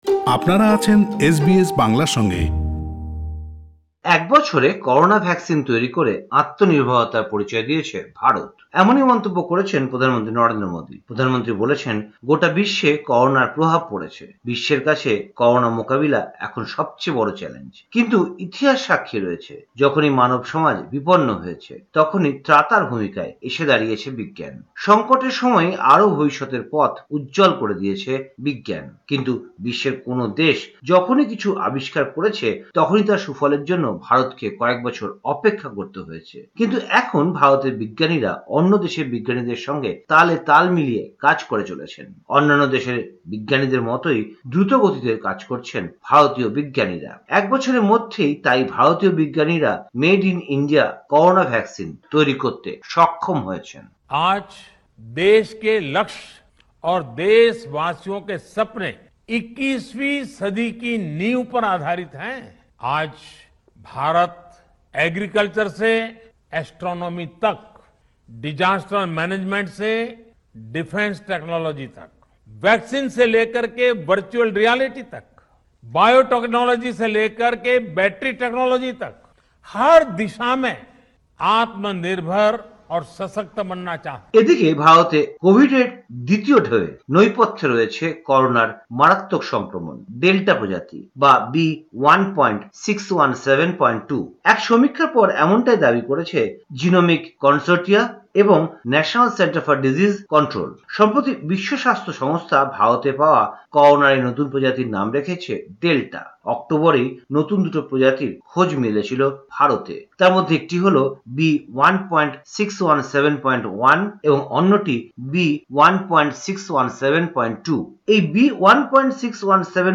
ভারতীয় সংবাদ: ৭ জুন ২০২১
কলকাতা থেকে